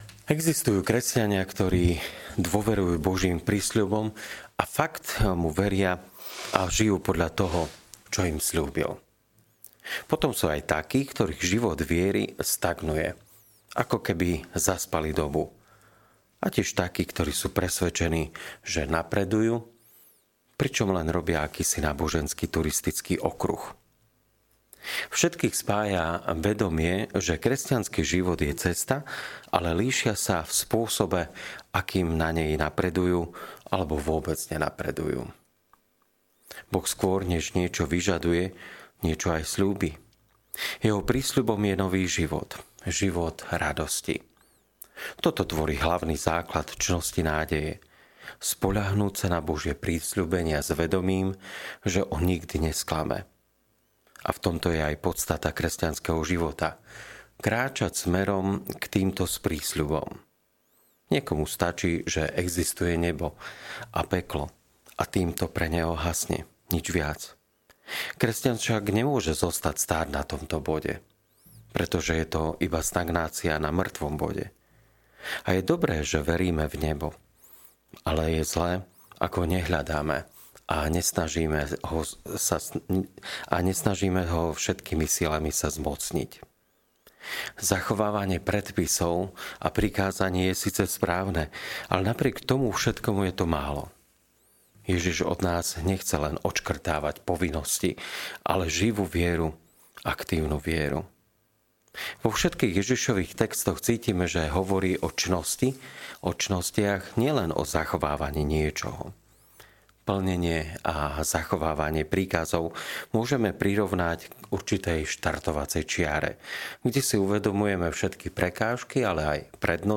Podcast Kázne